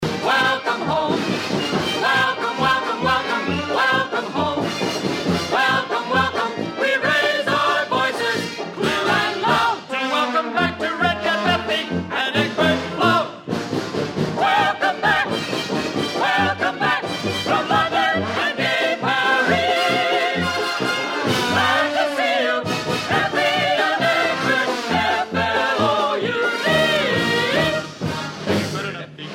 Original Cast